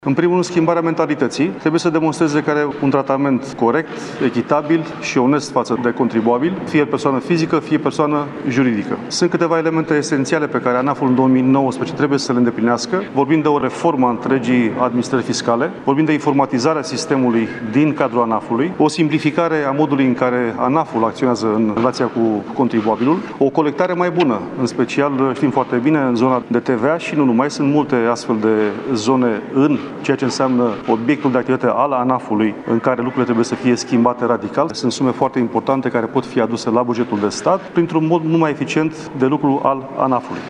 Ministrul Eugen Teodorovici și-a argumentat decizia prin faptul cã...